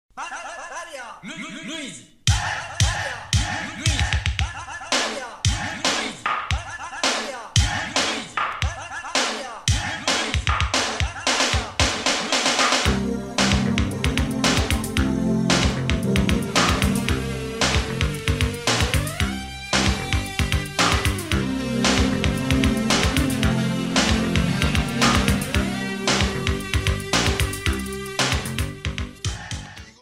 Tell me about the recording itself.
Cut off and fade-out